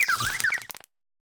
Grito de Tadbulb.ogg
) Categoría:Gritos de Pokémon de la novena generación Categoría:Tadbulb No puedes sobrescribir este archivo.
Grito_de_Tadbulb.ogg